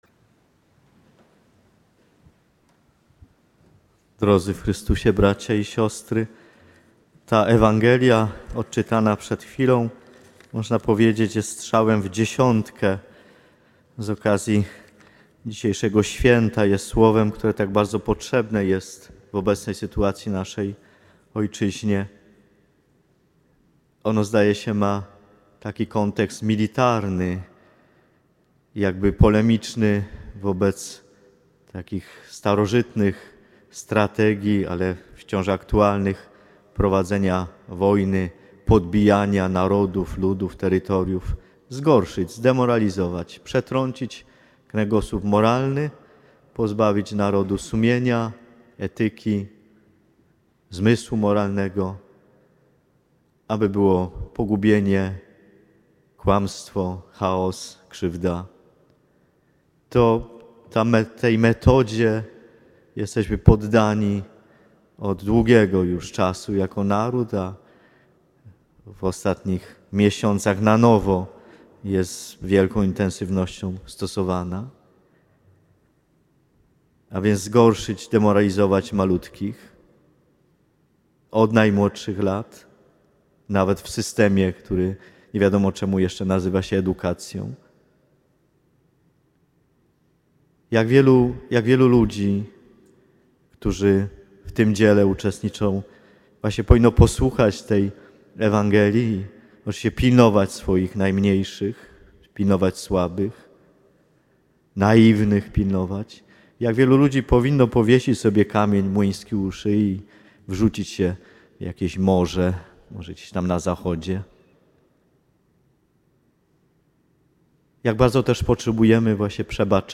W czasie liturgii wygłosił kazanie.